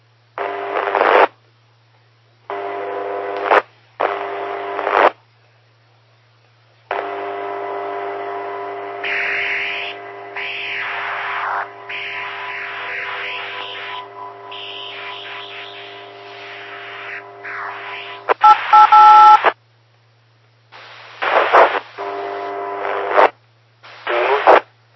Блуждабщая помеха
pomeha2.mp3